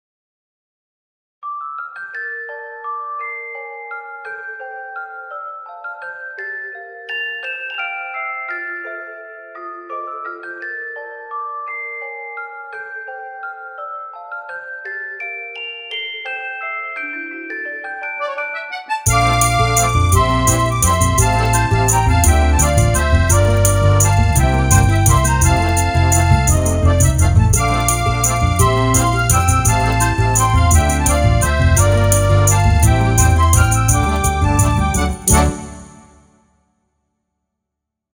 チェレスタから始まり後半はアコーディオンが華やかに展開します
ループせずに終わるのでタイトル画面やイベントシーンなどにどうぞ